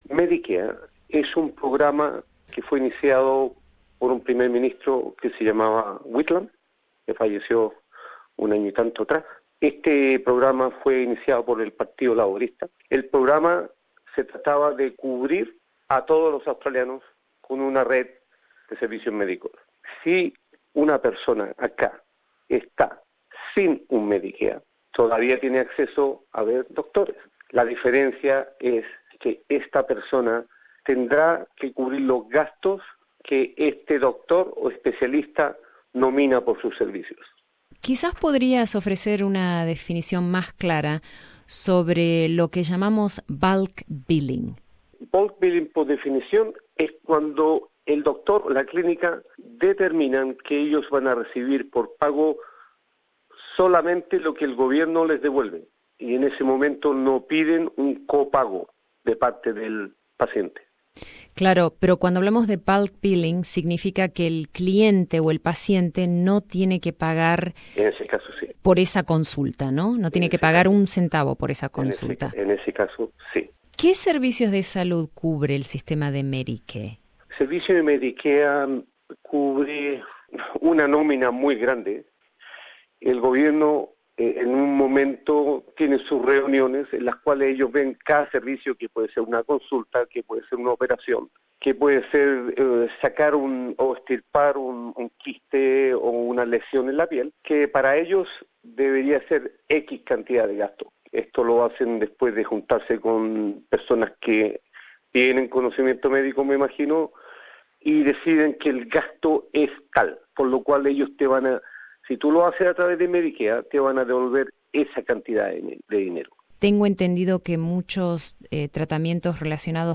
Escucha aquí nuestra entrevista